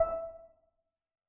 UI Sounds